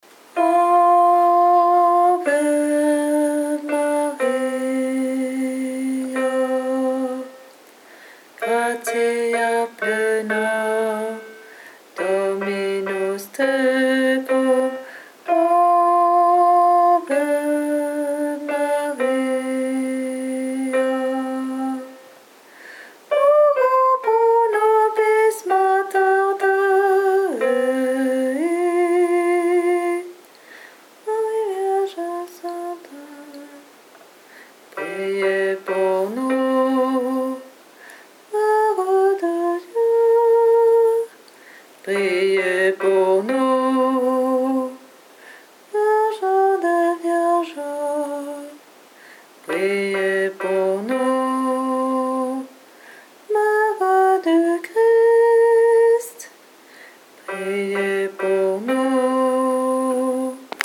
Voix chantée (MP3)COUPLET/REFRAIN
BASSE